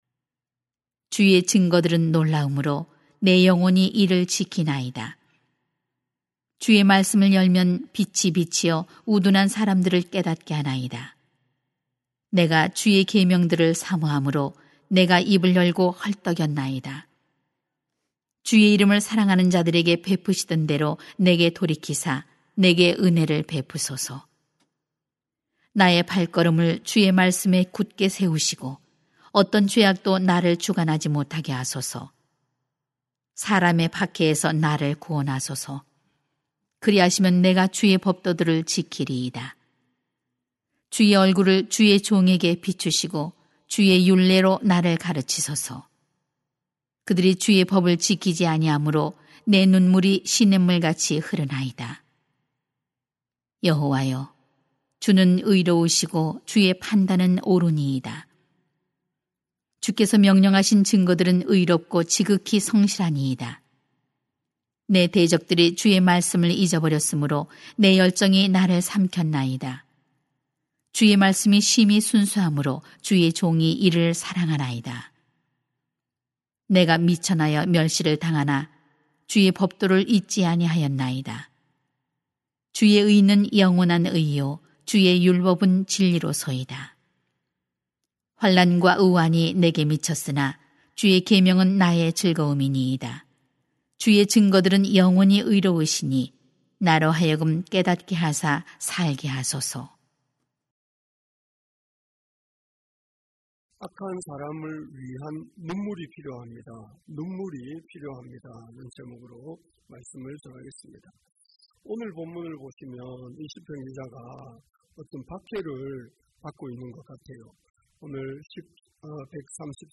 [시 119:129-144] 악한 사람을 위한 눈물이 필요합니다 > 새벽기도회 | 전주제자교회